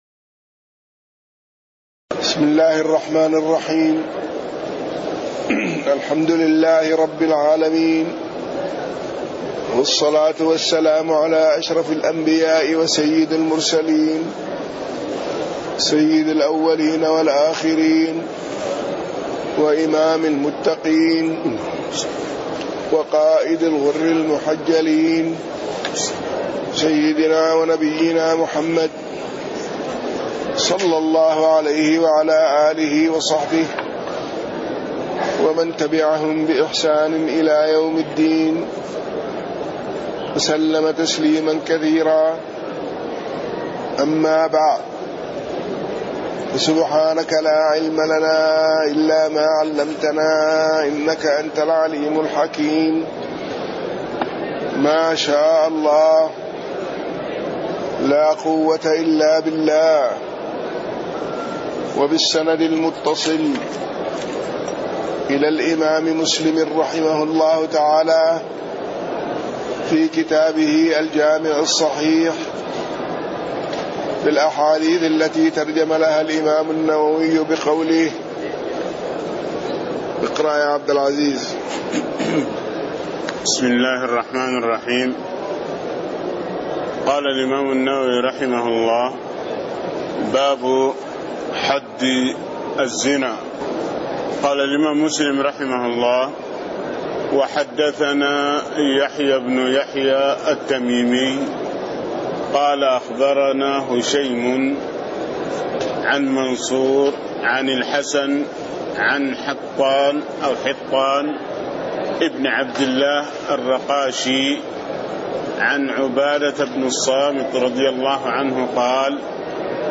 تاريخ النشر ٢٢ جمادى الآخرة ١٤٣٥ هـ المكان: المسجد النبوي الشيخ